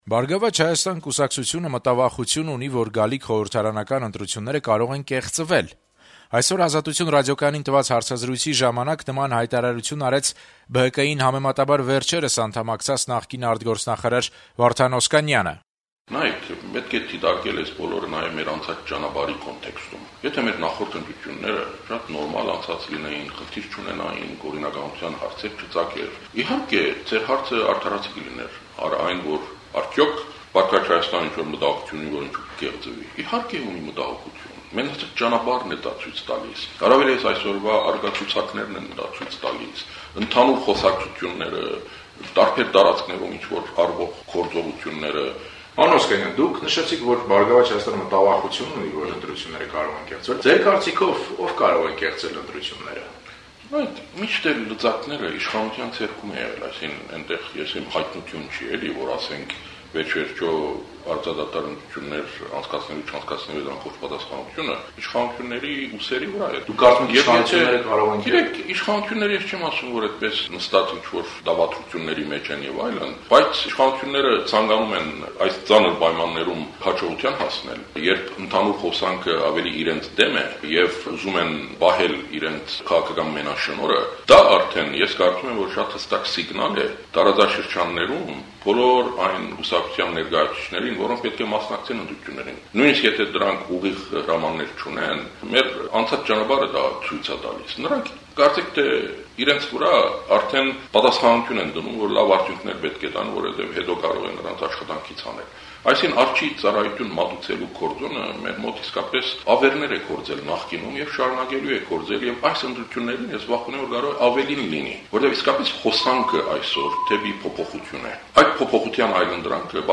Վարդան Օսկանյանը այդ մասին հայտարարեց «Ազատություն» ռադիոկայանին տված հարցազրույցում։